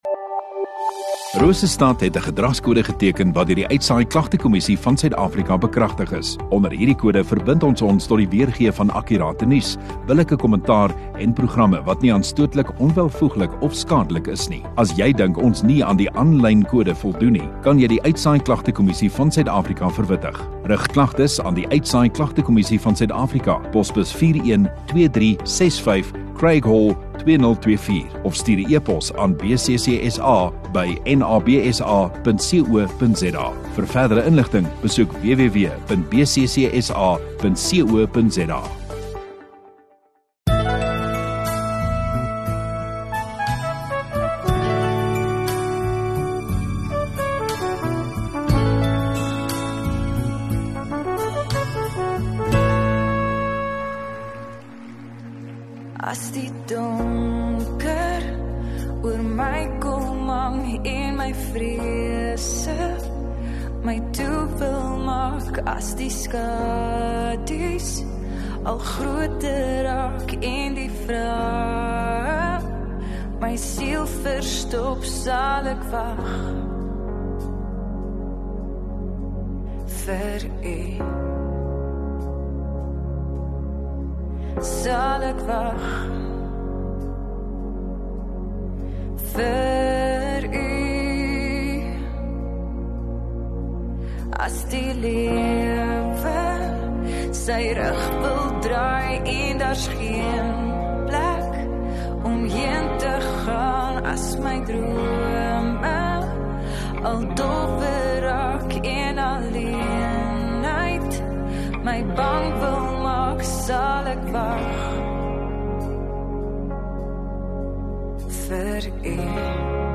Rosestad Godsdiens 24 Mar Sondagaand Erediens